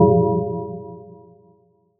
These are the individual element tones, produced with a decay. All tones were created using the spectral line frequencies which were then added together to create the element’s signature tone. An exponential decay was then applied to make the tone more pleasing to the ear.